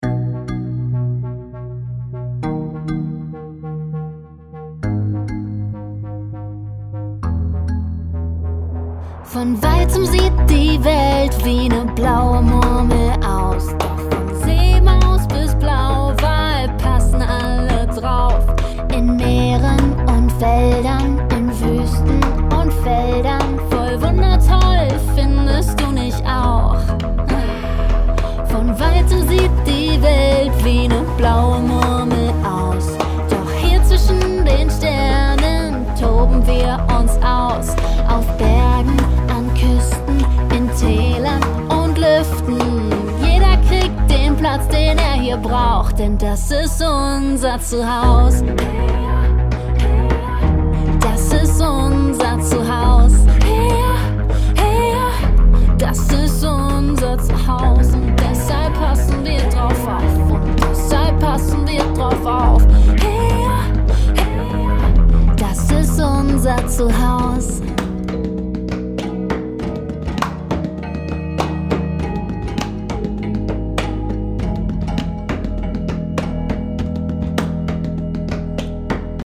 Ein Lied zum TAg der Erde mit einer Mission: Erde
Kindermusik